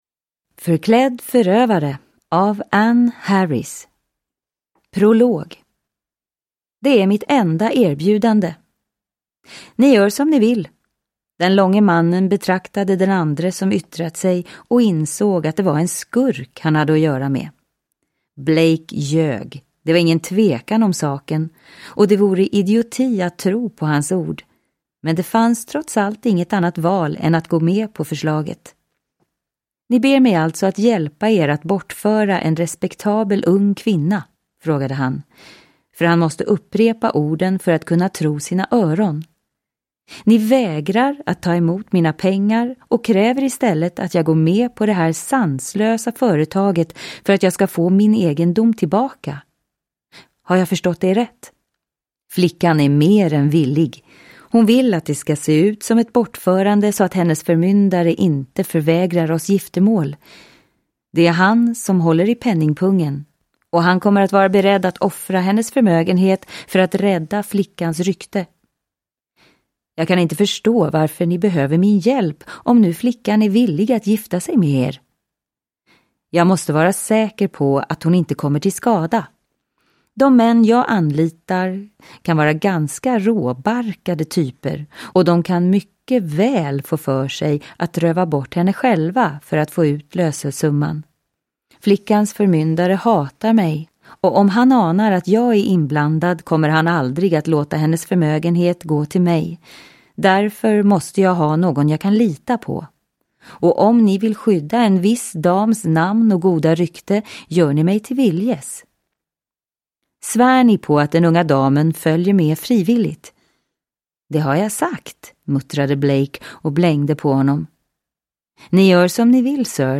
Förklädd förövare – Ljudbok – Laddas ner